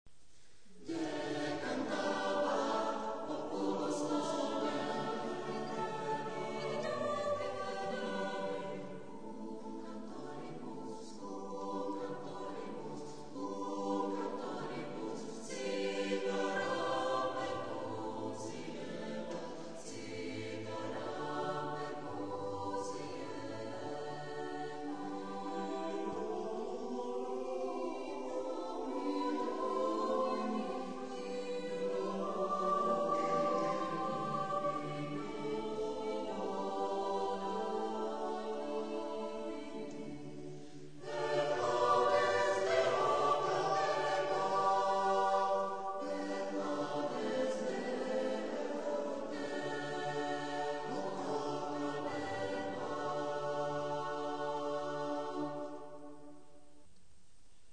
Smíšený pěvecký sbor SalvátorSalvátor
V této sekci najdete některé z amatérských nahrávek či videí našeho sboru.